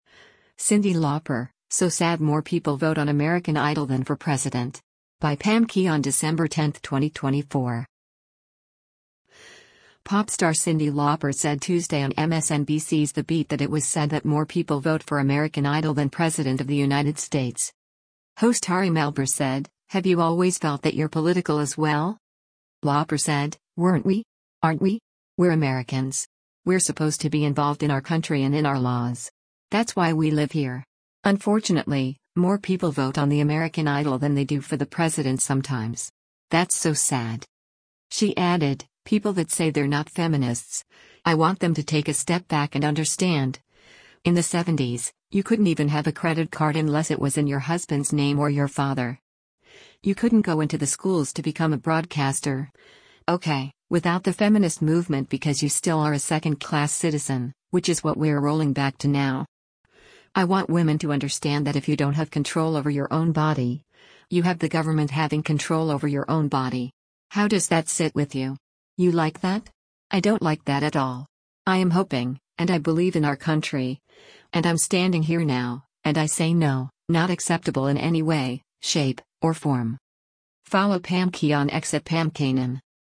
Pop star Cyndi Lauper said Tuesday on MSNBC’s “The Beat” that it was “sad” that more people vote for “American Idol” than President of the United States.
Host Ari Melber said, “Have you always felt that you’re political as well?”